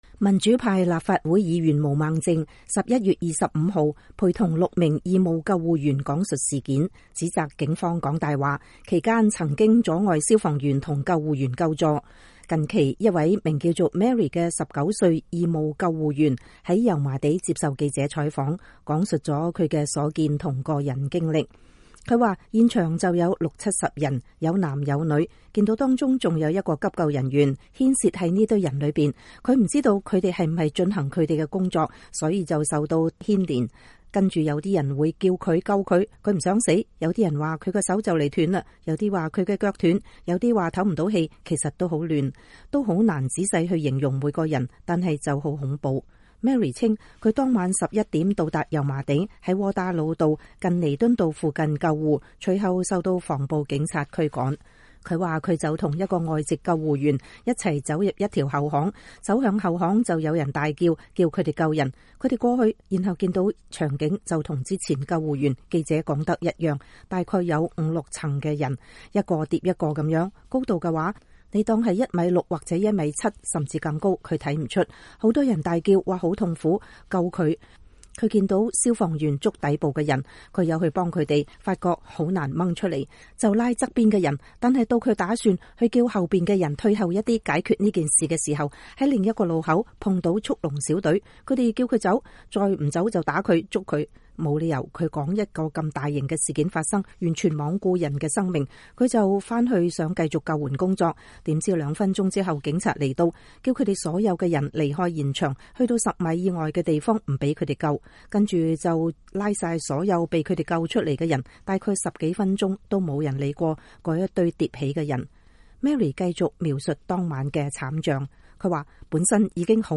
大學生義務急救員